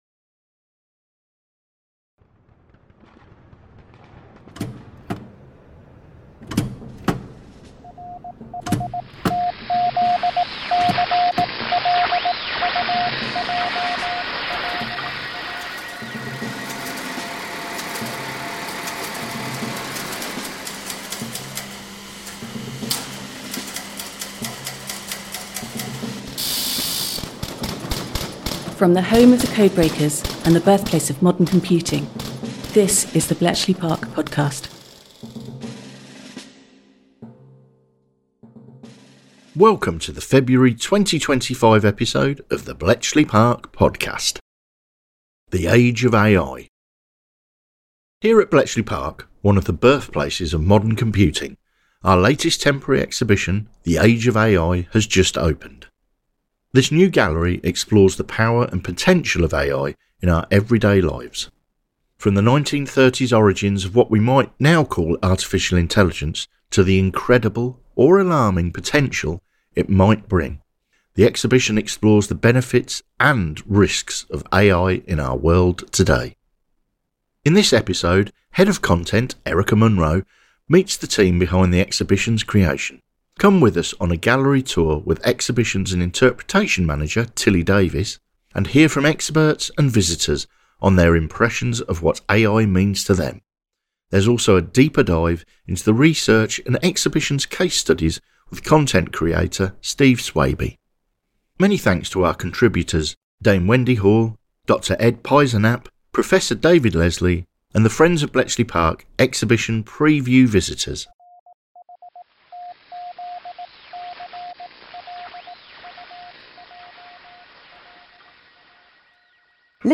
Come with us on a gallery tour
and hear from experts and visitors on their impressions of what AI means to them.